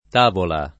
tavola [ t # vola ]